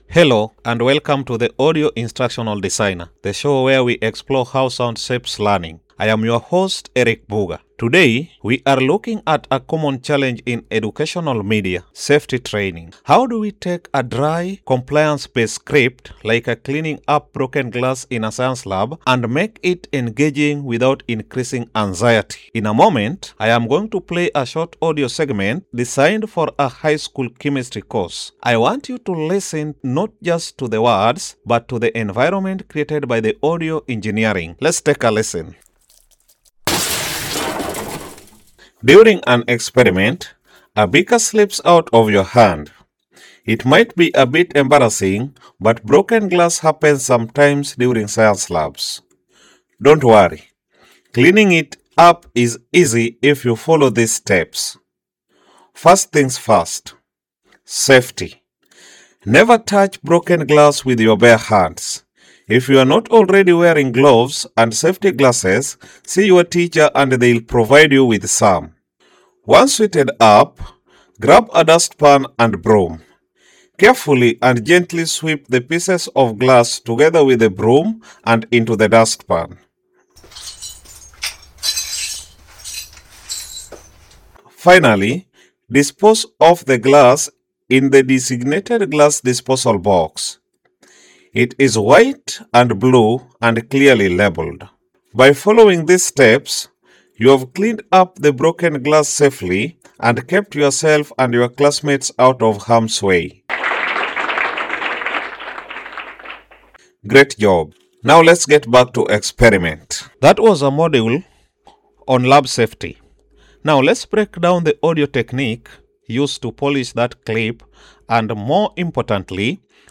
2. Hardware: Fifine Microphone.
Key techniques include: Signaling: Using auditory cues (sound effects) to prime the learner for critical events. Dual Coding: Reinforcing verbal instructions with corresponding environmental sounds. Vocal Modulation: Utilizing tone and pacing to manage the learner’s affective state during high-stress scenarios.